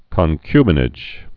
(kŏn-kybə-nĭj, kən-)